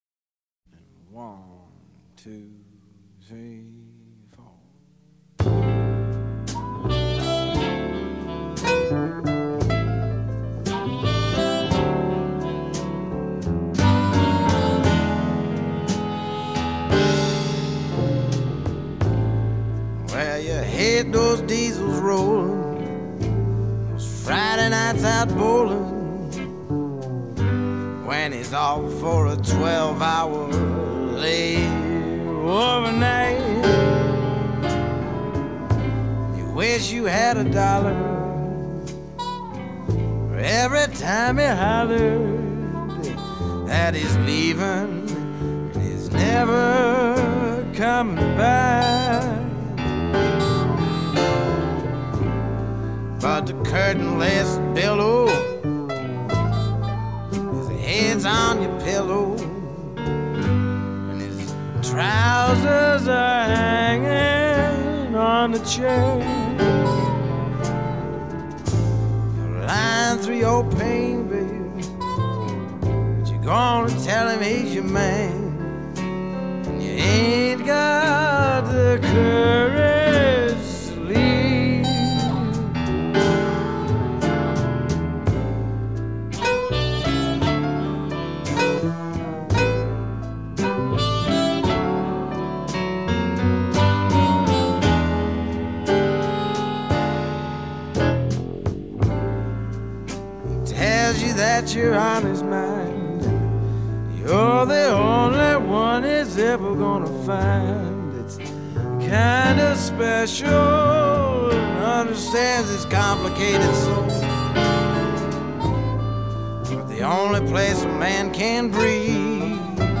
jazzy-lounge vocal performance